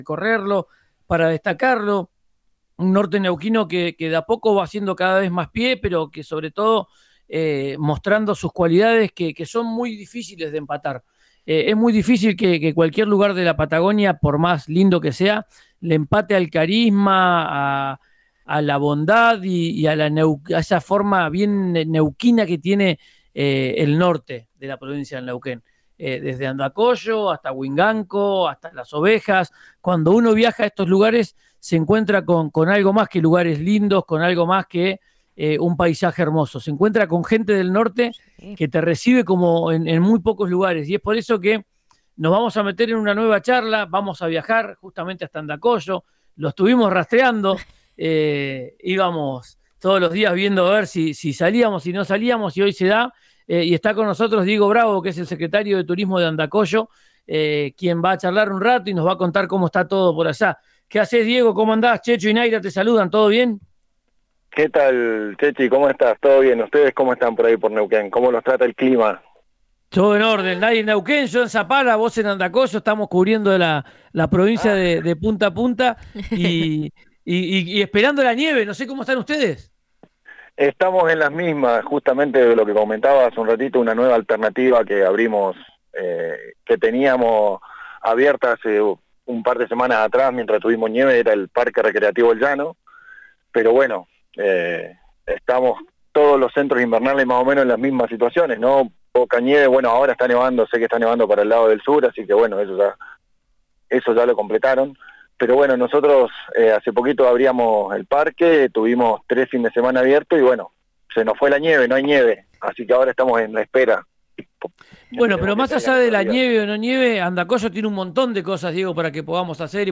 “Este verano se notó más”, aseguró el funcionario en diálogo con Marca Patagonia por RÍO NEGRO RADIO.